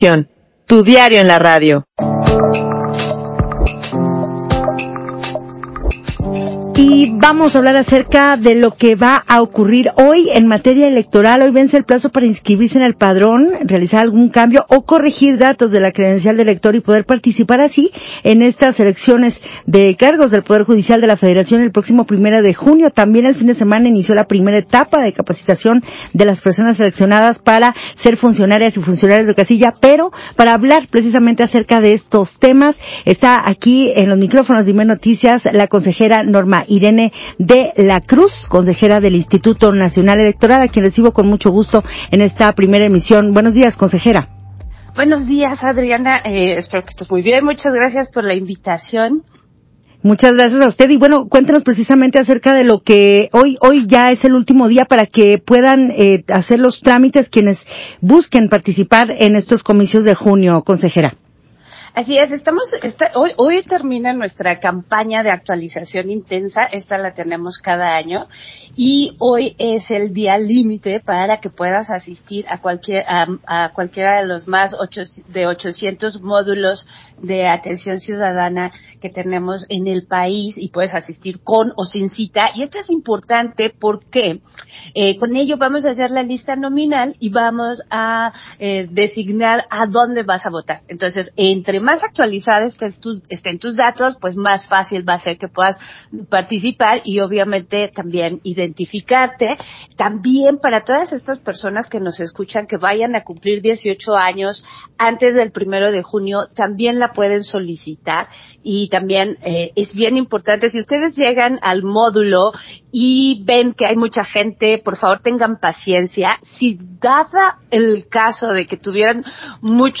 Entrevista de la Consejera Electoral Norma De La Cruz